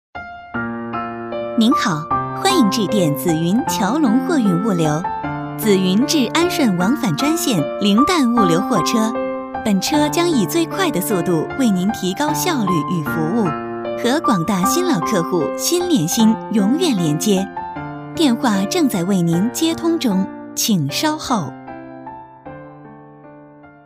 女声配音
彩铃女国89B